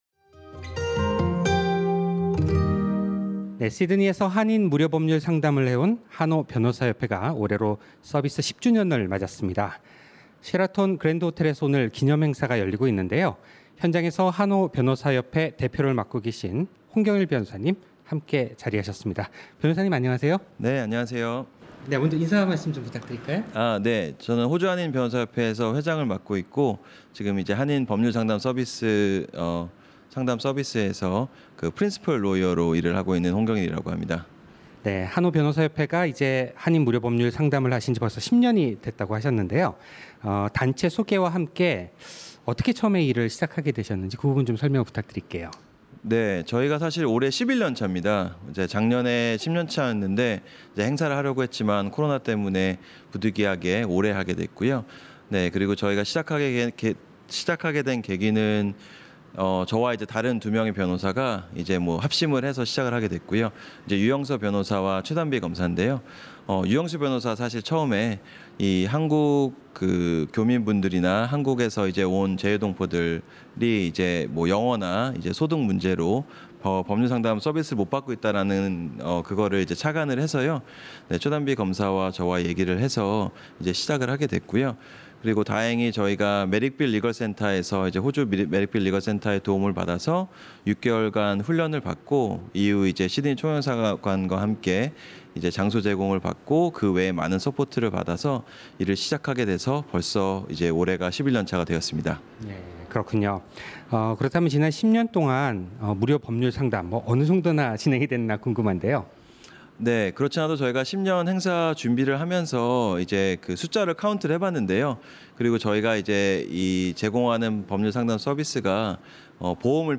셰라톤 그랜드 호텔에서 오늘 기념행사가 열리고 있는데요.